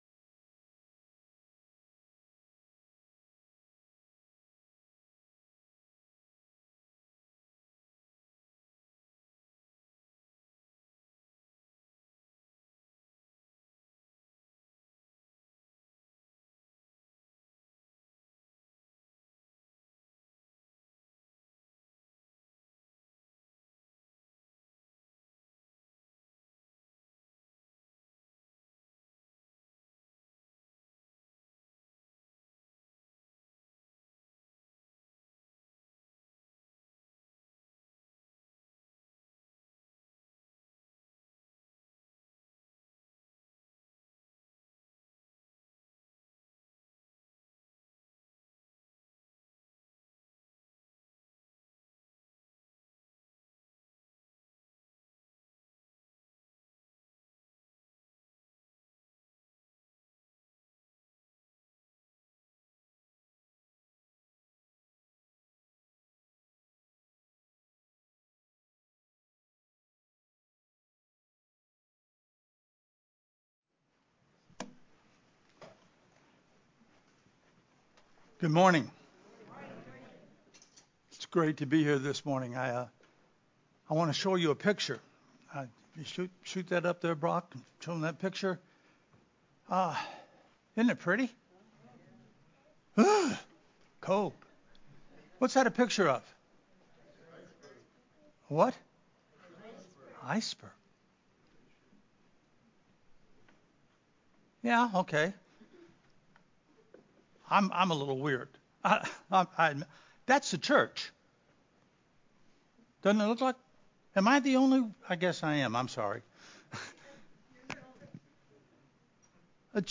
The-Principles-of-Leadership-Sermon-Audio-CD.mp3